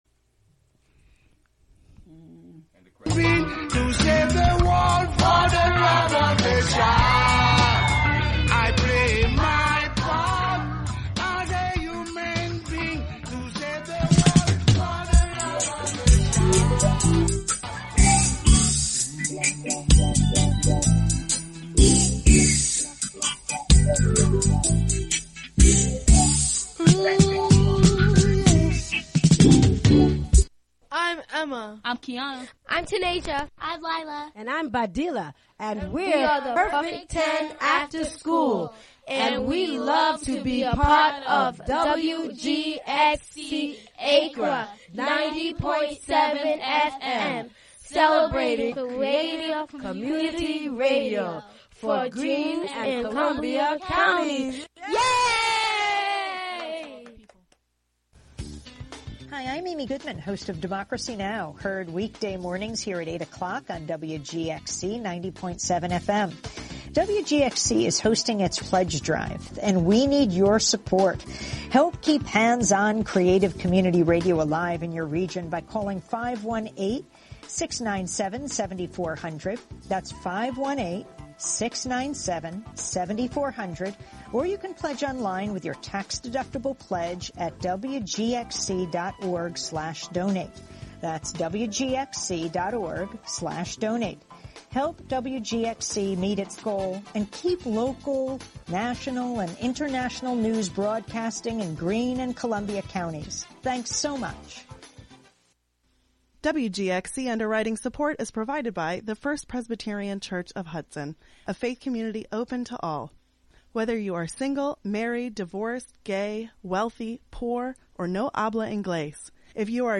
7pm Monthly program featuring music and interviews.